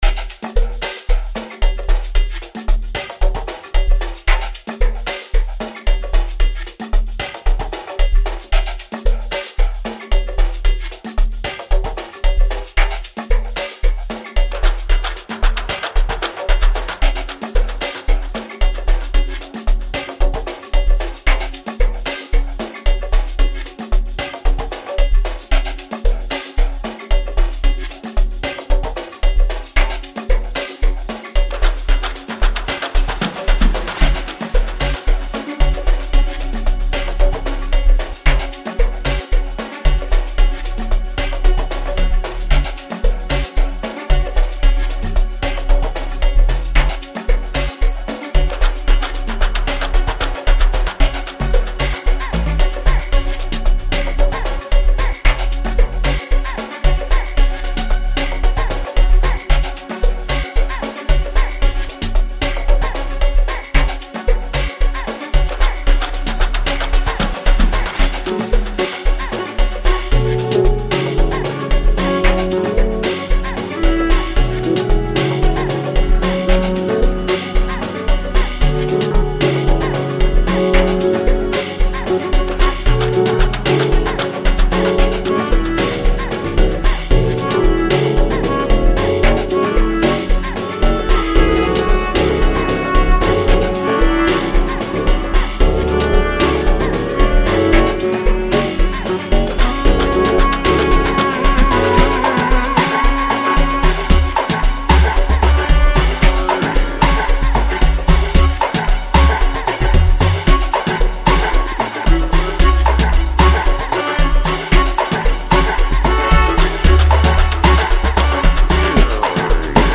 instrumental
Amapiano